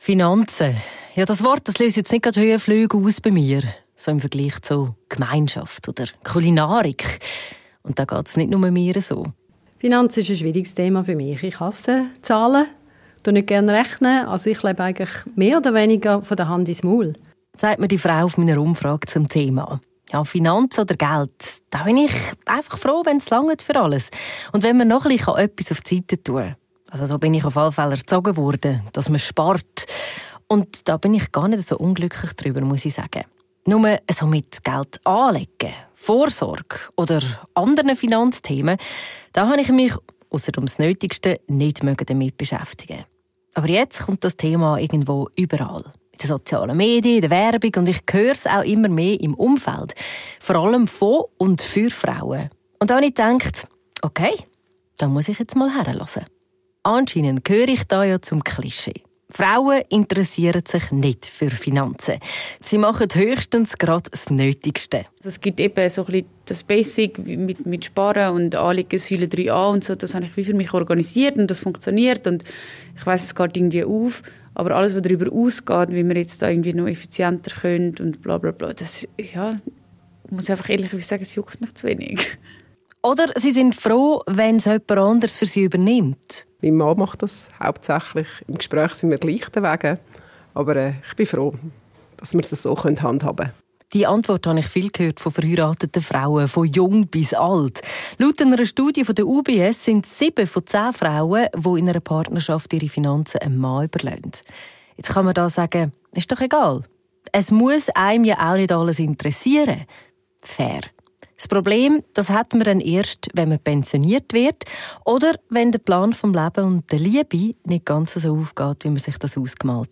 Mit Christina Aus der Au, Kirchenratspräsidentin, Theologin und Ethikerin, sprechen wir über nachhaltige Investments, ethische Abwägungen und die Frage, wie man Geld, Glauben und Werte in Einklang bringen kann.